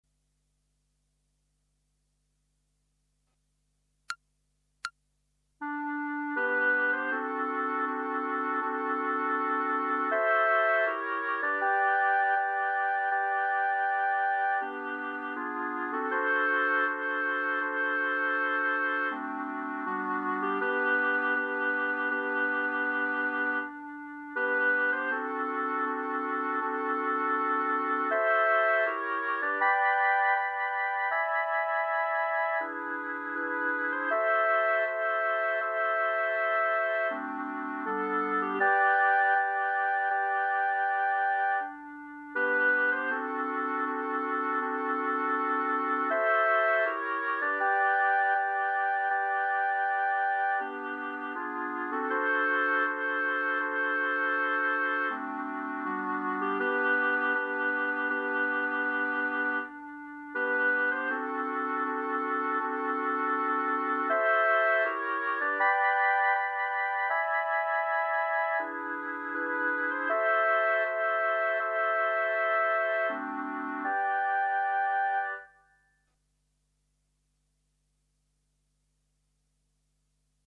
Clarinet Ensemble